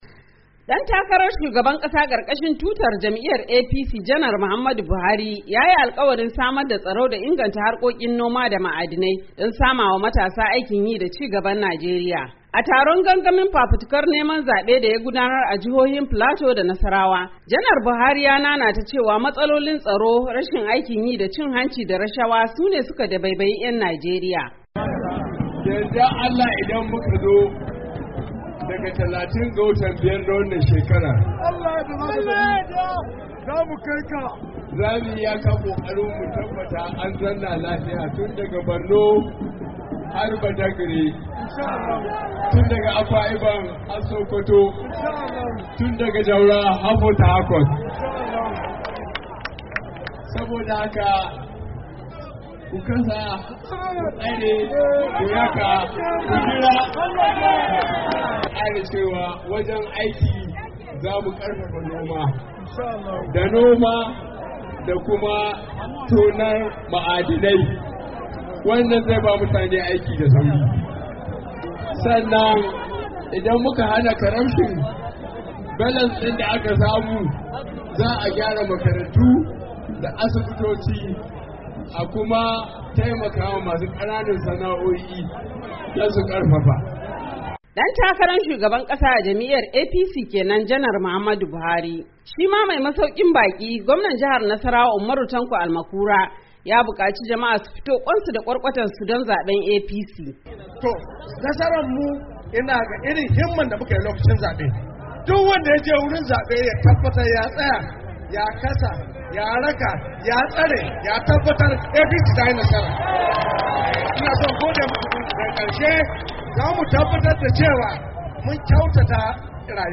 A taron gangamin fafitikar neman zabe da ya gudanar a jihohin Filato da Nasarawa Janaral Buhari ya nanata cewa matsalolin tsaro, rashin aikin yi da cin hanci da rashawa su ne suka yiwa 'yan Najeriya katutu.